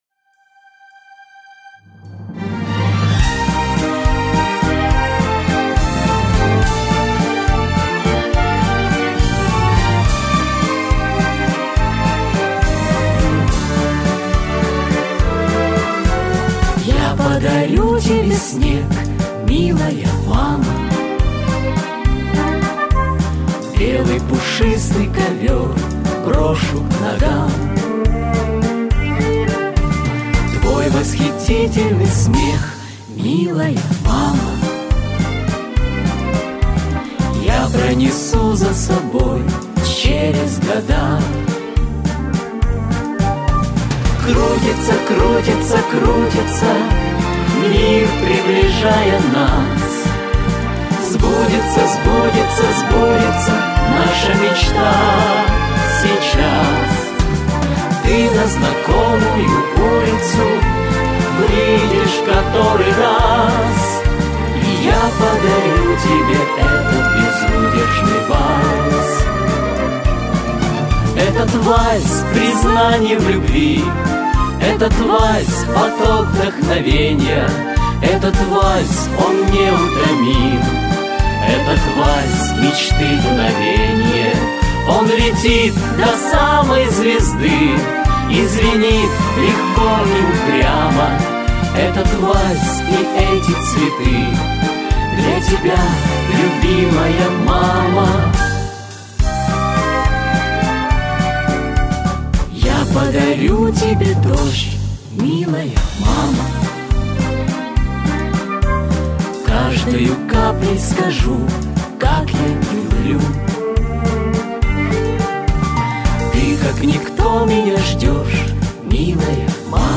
• Качество: Хорошее
• Категория: Детские песни
теги: мама, день матери, вальс, танец, минус, 8 марта